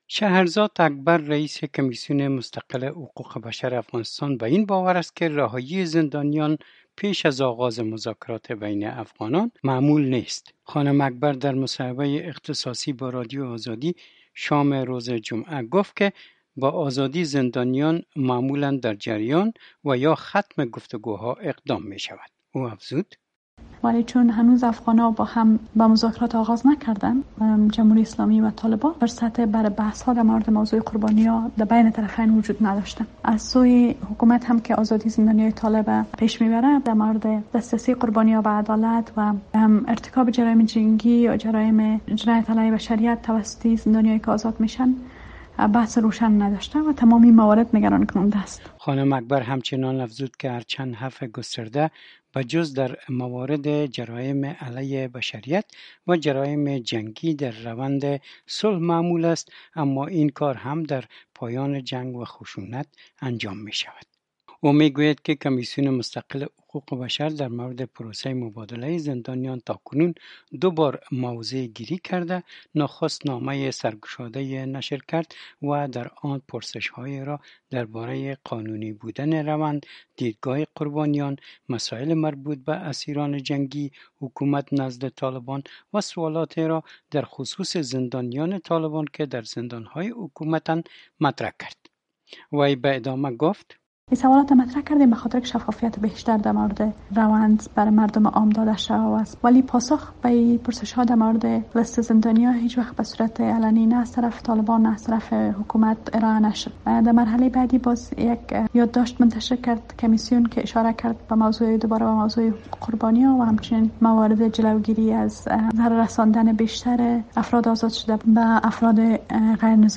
خانم اکبر در مصاحبه شام جمعه با رادیو آزادی افزود که در جریان مبادلۀ قربانیان آنها دو بار موضع‌گیری شان را اعلام کرده و پرسش‌های شان را مطرح نموده اند.